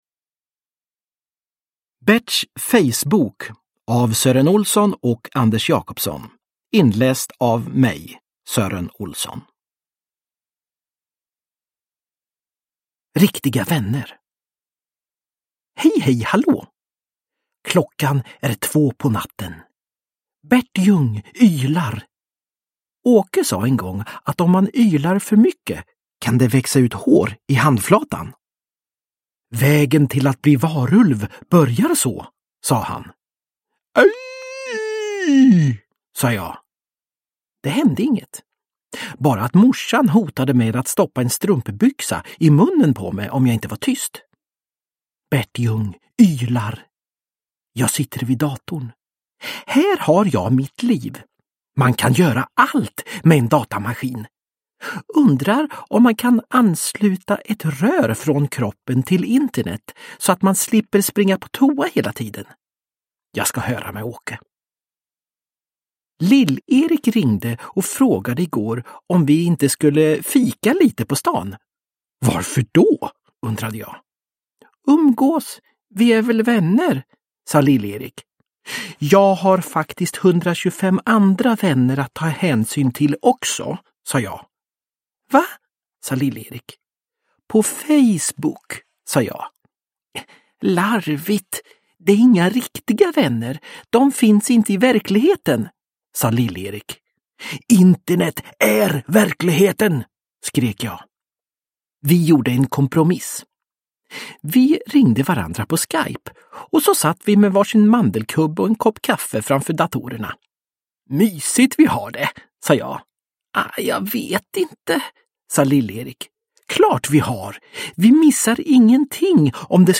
Berts fejsbok – Ljudbok – Laddas ner
Uppläsare: Sören Olsson